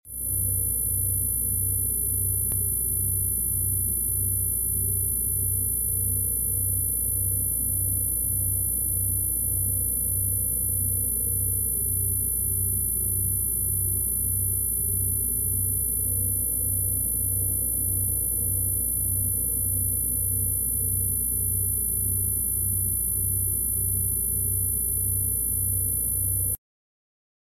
Frequency meditation for DMT, the